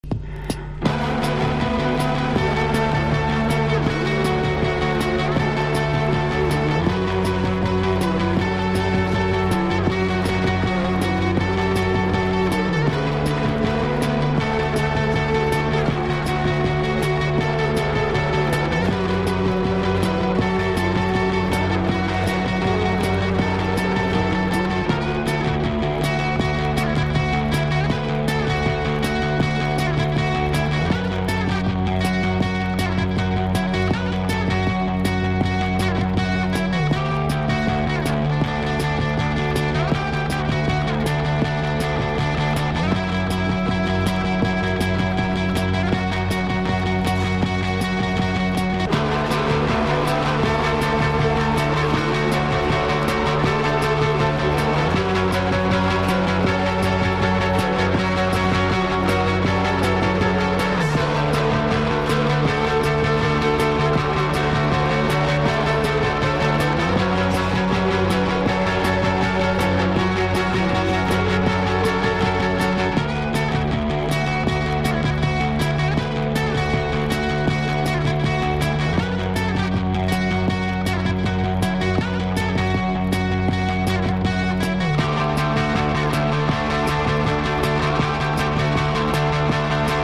1. 00S ROCK >
POST PUNK